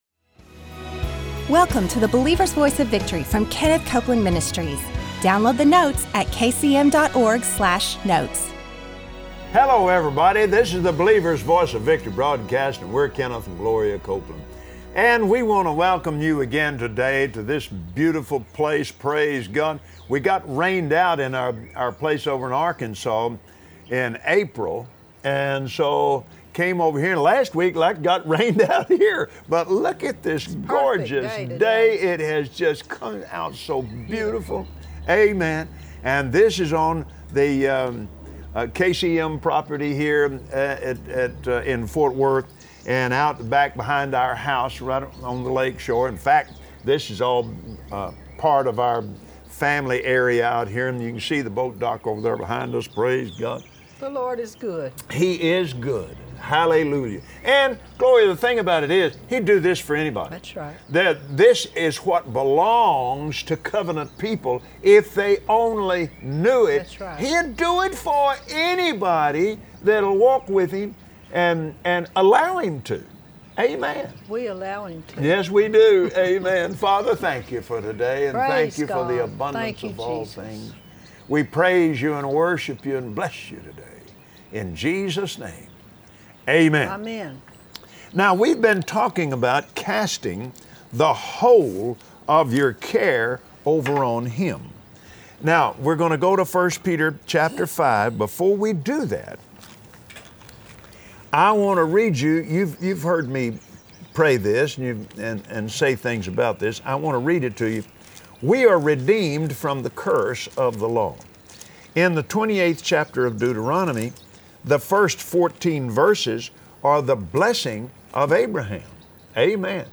Believers Voice of Victory Audio Broadcast for Friday 07/15/2016 Today on the Believer’s Voice of Victory, Kenneth and Gloria Copeland teach us how to destroy the worry habit. Stop the cycle of worrying, once and for all.